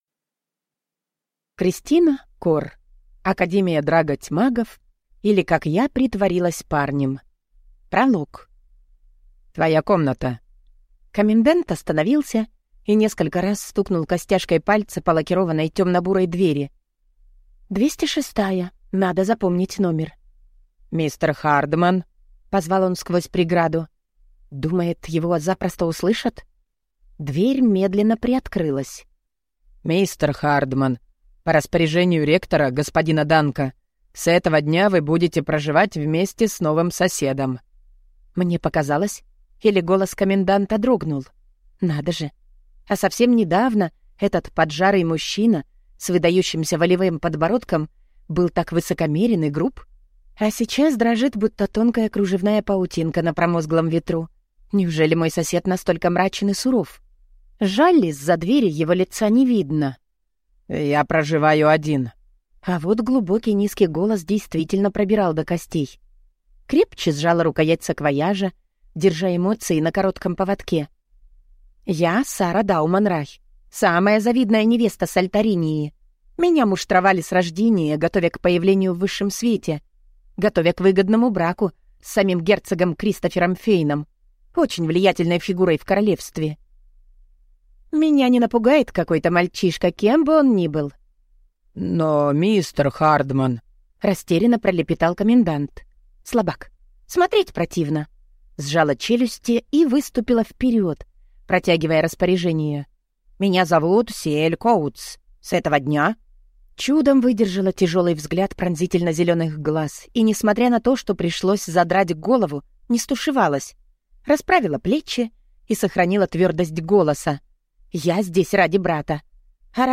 Аудиокнига Академия Драго Тьмагов, или Как я притворилась парнем | Библиотека аудиокниг